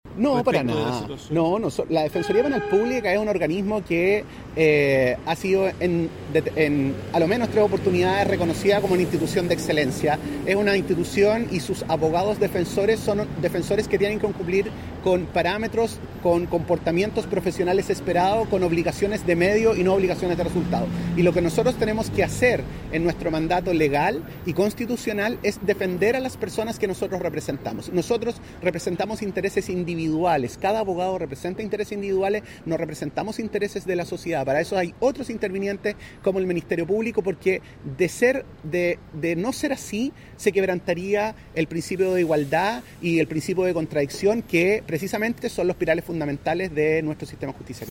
Justamente respecto a esa solicitud fue la primera pregunta al defensor regional del Bío Bío, Osvaldo Pizarro, durante un punto de prensa esta semana, al pedirle explicar la razonabilidad detrás de la petición.